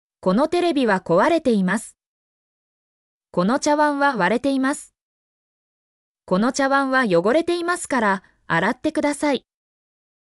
mp3-output-ttsfreedotcom-51_mMTcFK64.mp3